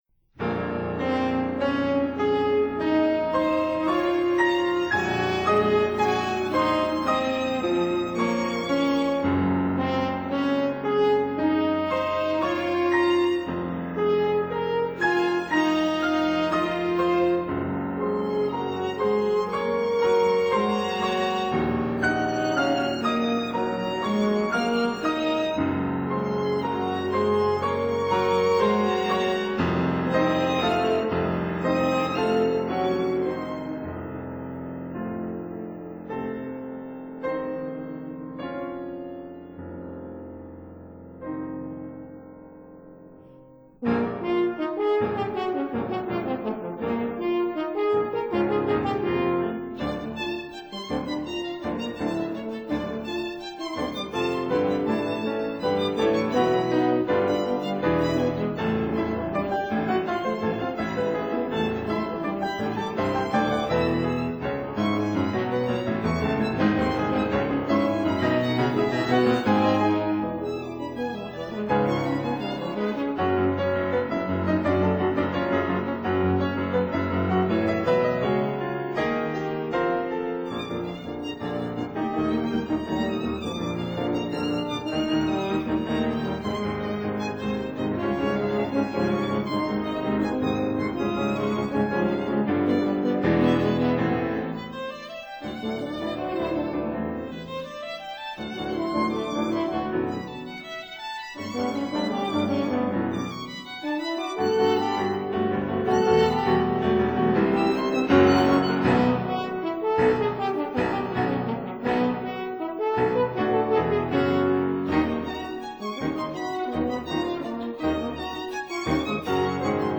Trio for Horn, Violin and Piano
violin
piano